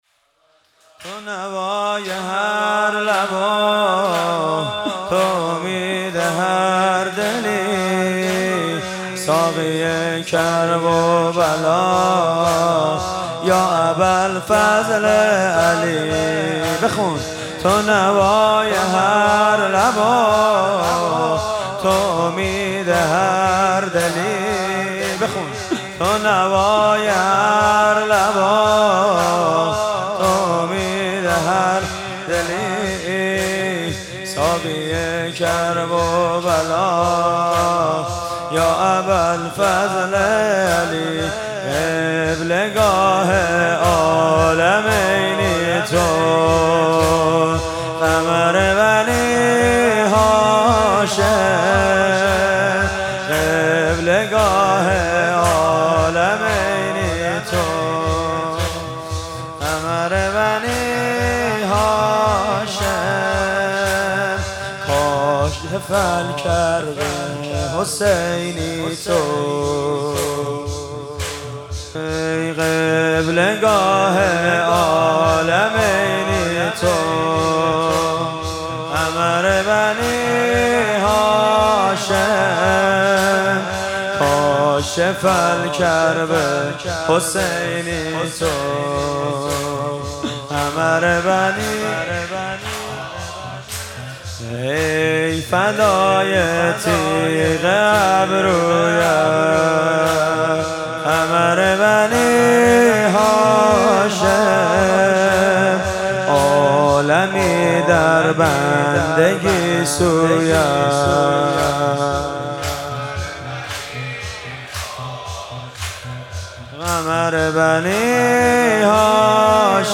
محرم1401 - شب نهم - زمینه - قبله گاه عالمینی تو